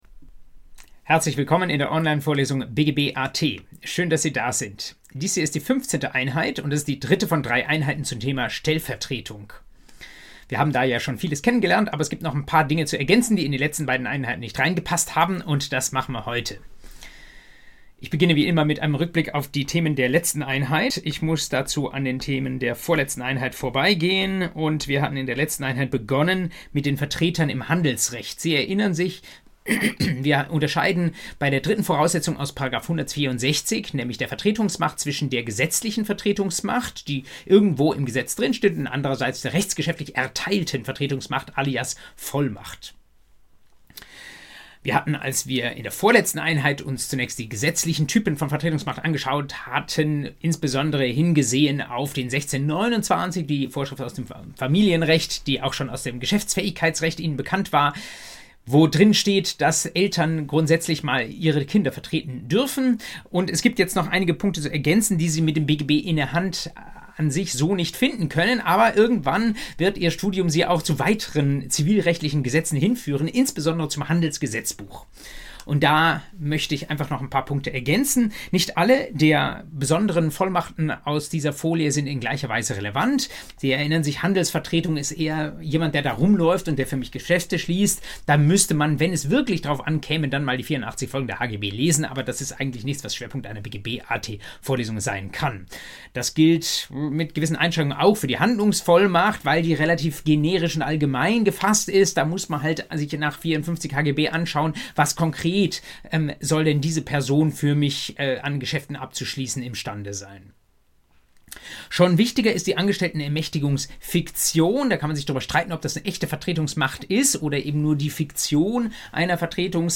BGB AT Folge 15: Stellvertretung III ~ Vorlesung BGB AT Podcast